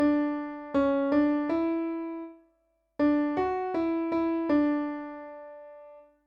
Practice finding the tonic in a minor key
Question 3: E